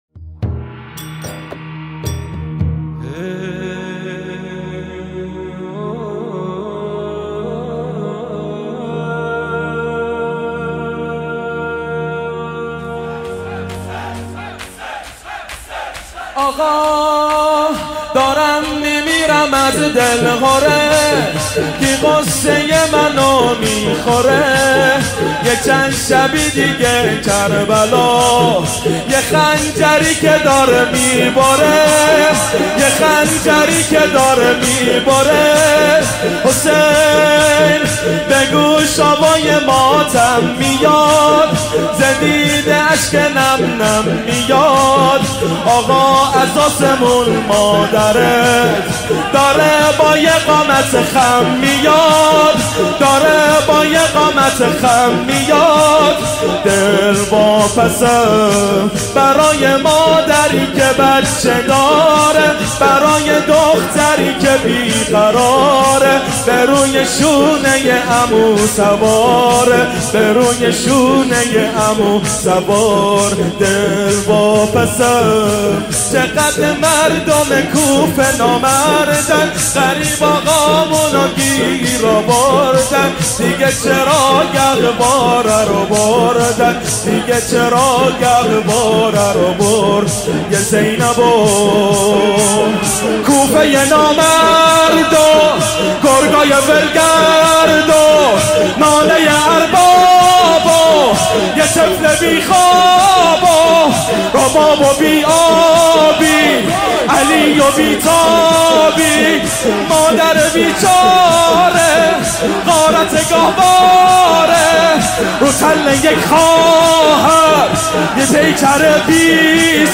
شور | آقا دارم می‌میرم از دلهره
مداحی
هیأت علی اکبر بحرین